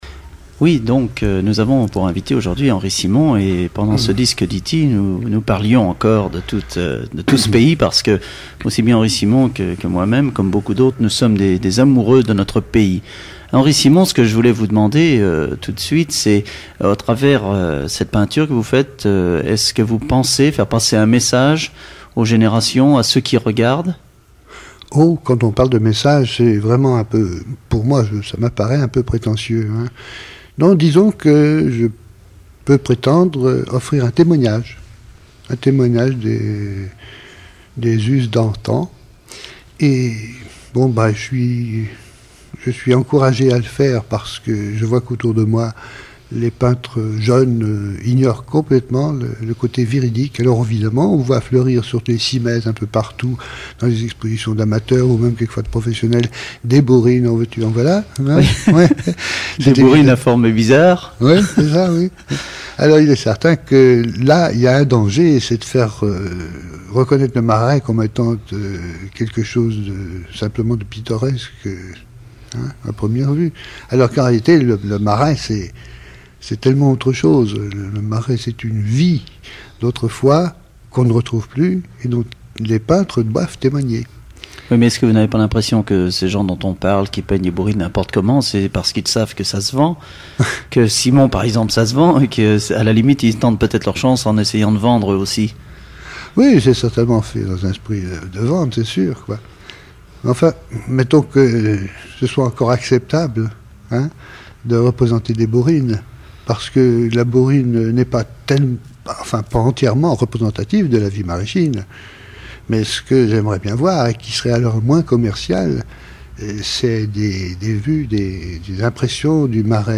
Enquête Alouette FM numérisation d'émissions
Catégorie Témoignage